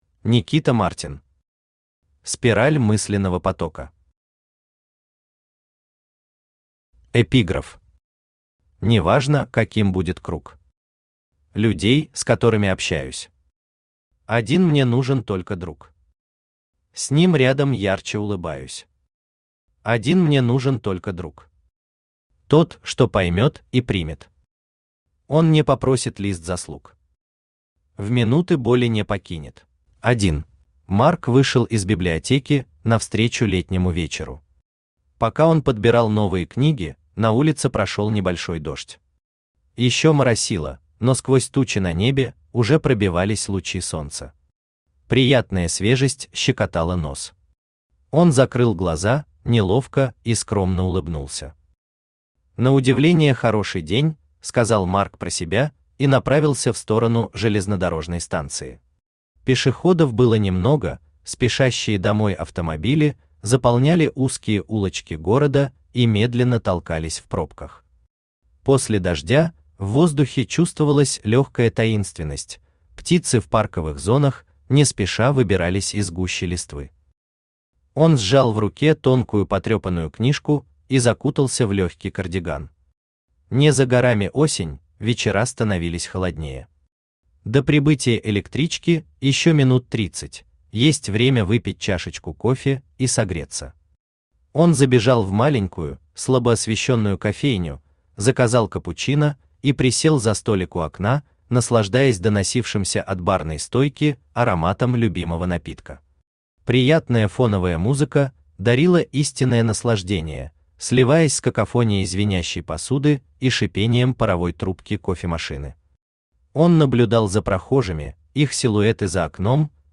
Читает: Авточтец ЛитРес
Аудиокнига «Спираль мысленного потока».